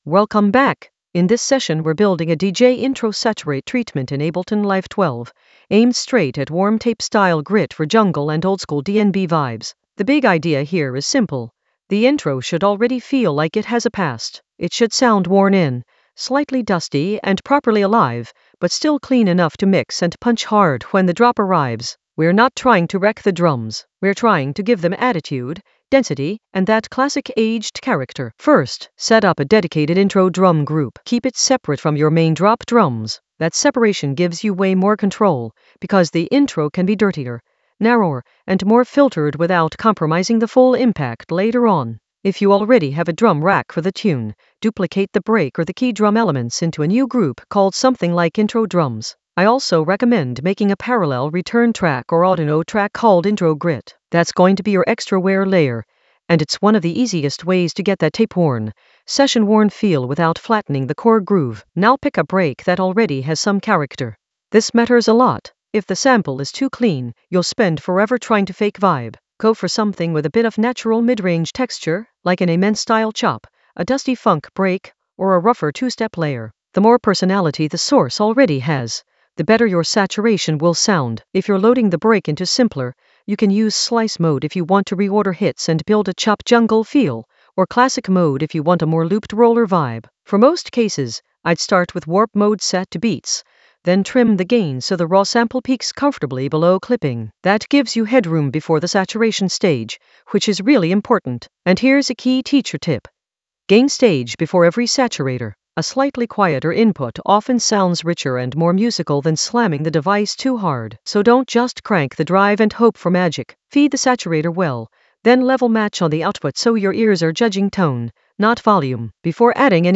Narrated lesson audio
The voice track includes the tutorial plus extra teacher commentary.
An AI-generated intermediate Ableton lesson focused on DJ intro saturate session for warm tape-style grit in Ableton Live 12 for jungle oldskool DnB vibes in the Drums area of drum and bass production.